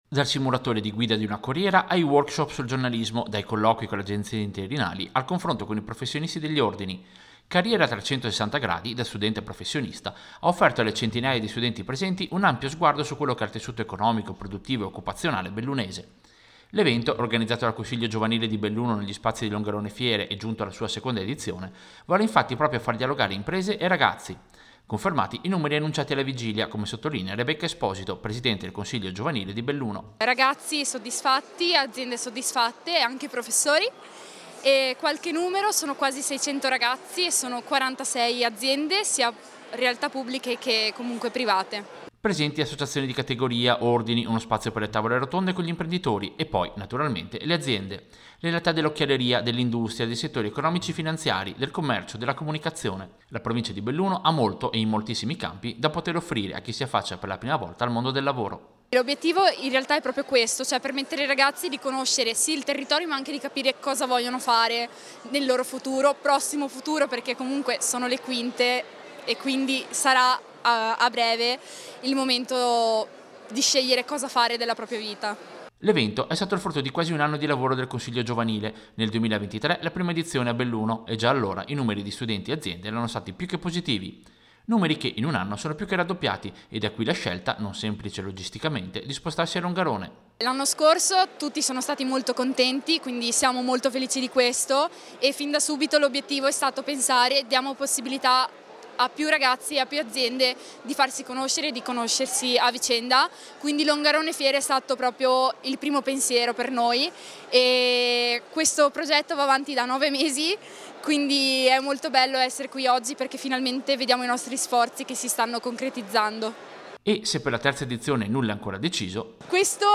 Servizio-Seconda-edizione-Carriera-360.mp3